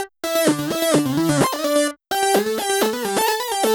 Index of /musicradar/french-house-chillout-samples/128bpm/Instruments
FHC_Arp C_128-E.wav